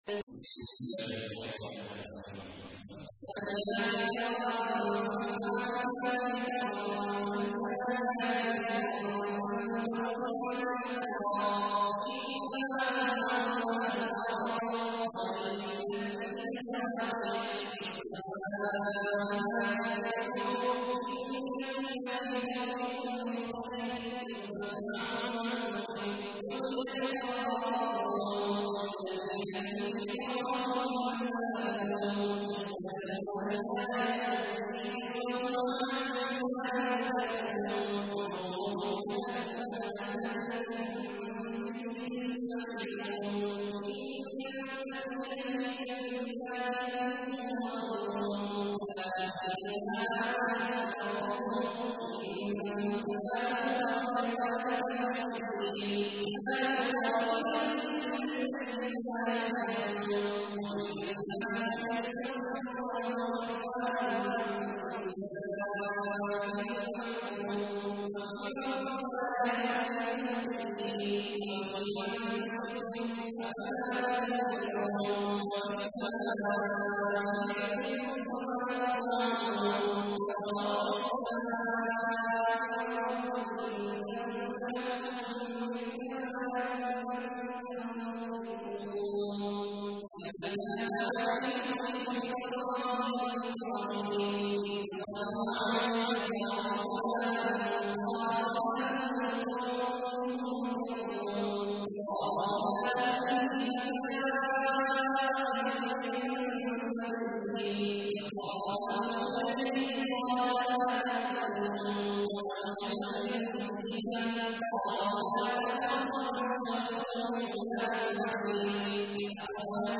تحميل : 51. سورة الذاريات / القارئ عبد الله عواد الجهني / القرآن الكريم / موقع يا حسين